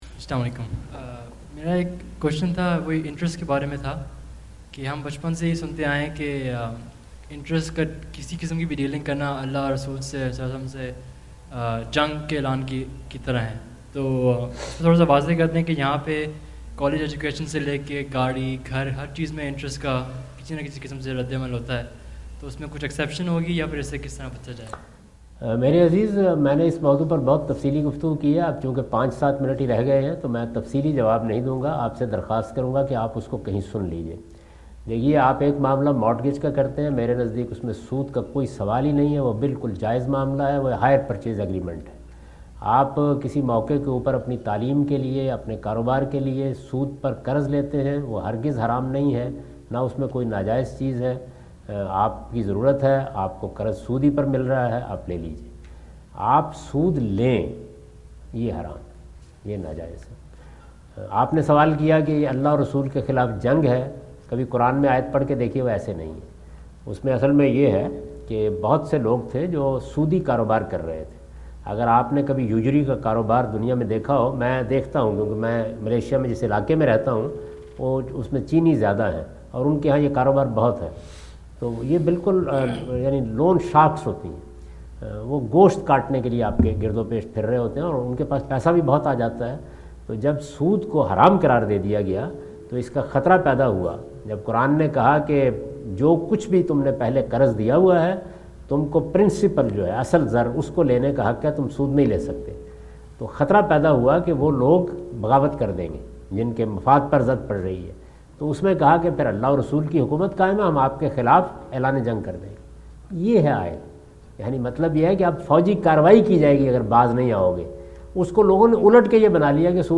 Javed Ahmad Ghamidi answer the question about "Taking Interest Equals War with God" During his US visit in Dallas on October 08,2017.
جاوید احمد غامدی اپنے دورہ امریکہ2017 کے دوران ڈیلس میں "کیا سود لینا خدا اور رسول کے خلاف جنگ ہے؟" سے متعلق ایک سوال کا جواب دے رہے ہیں۔